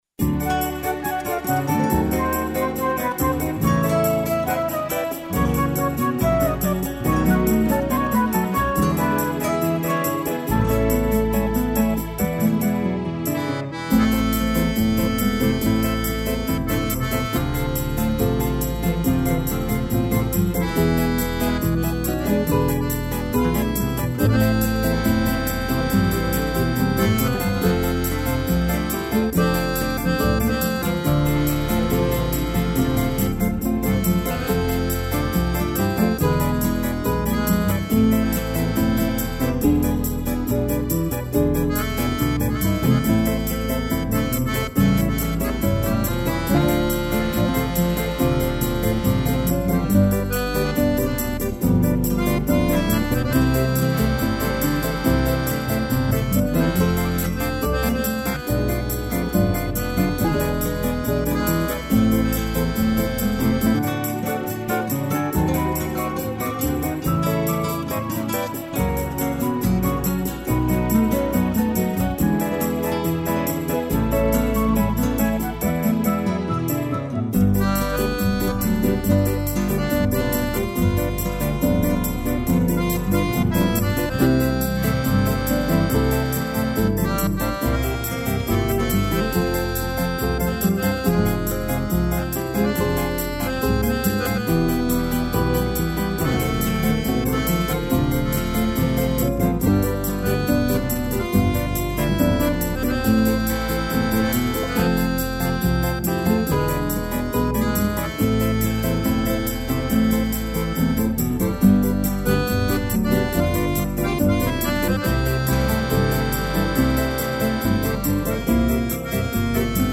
instrumental
violão